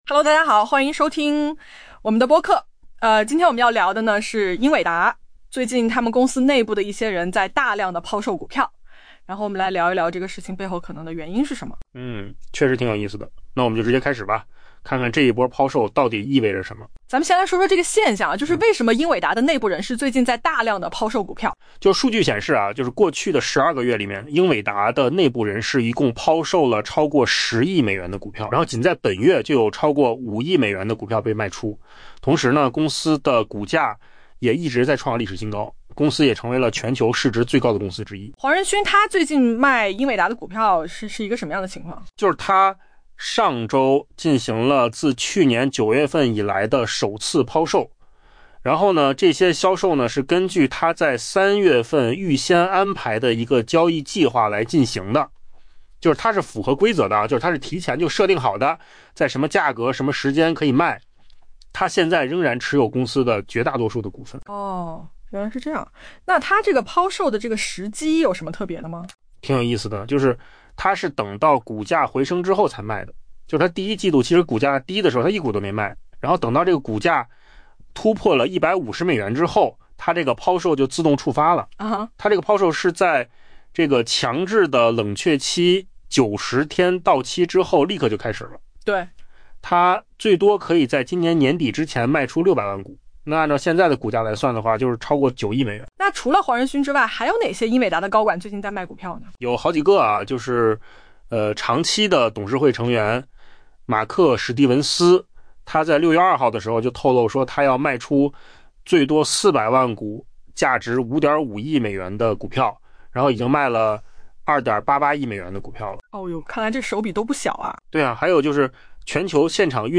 AI播客：换个方式听播客 下载mp3
音频由扣子空间生成